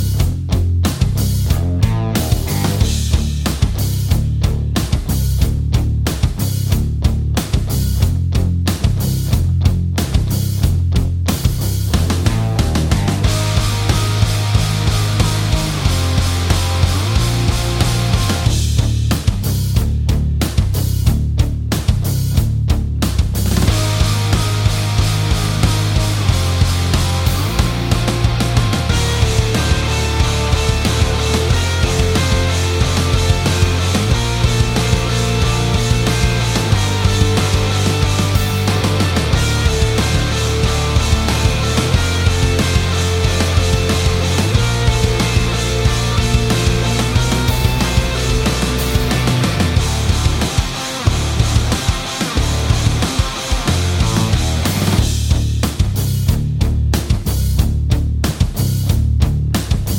no Backing Vocals Rock 4:05 Buy £1.50